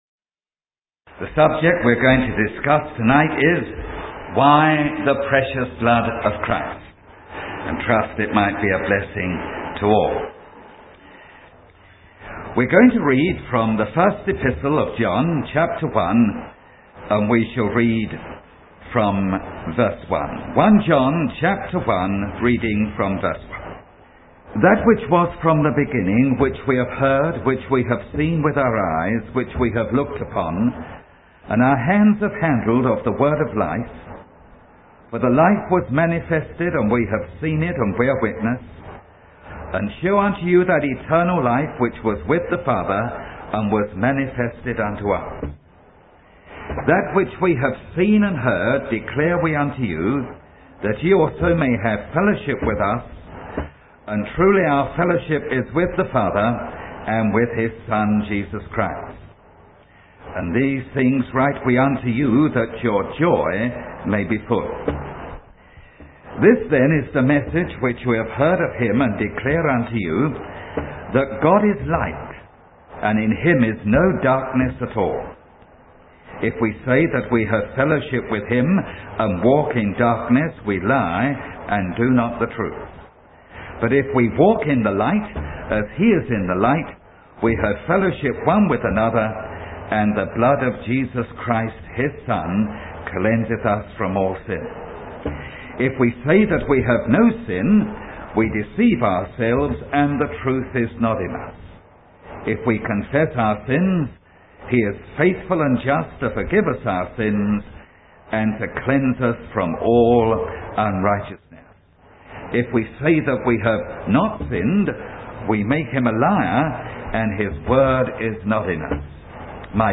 He contrasts parental forgiveness with judicial forgiveness, emphasizing that God must remain just while offering mercy. The sermon highlights the authority and dignity of Christ's blood, which cleanses all sins for all people, and the importance of walking in the light to maintain fellowship with God.